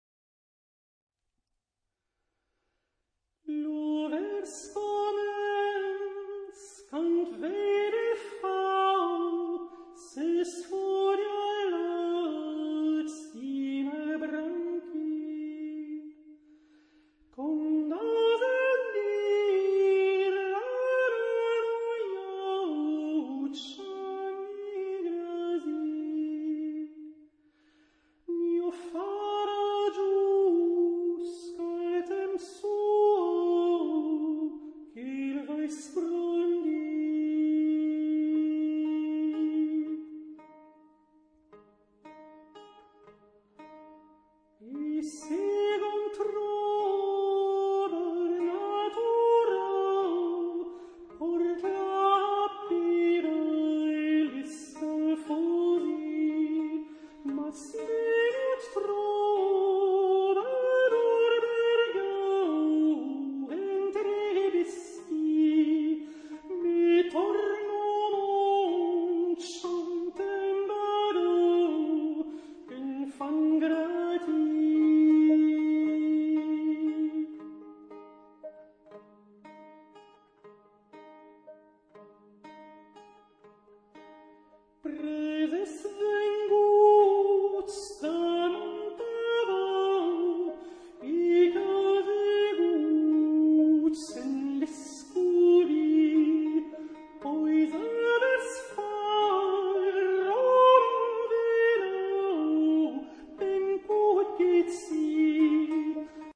都是12世紀法國Occitane地區遊唱詩人作品，
風格素雅、簡單、但情感深邃。
簡單的古樂伴奏，加上單一男聲、女聲，在12世紀那個黑暗的時代裡，